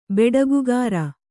♪ beḍagugāra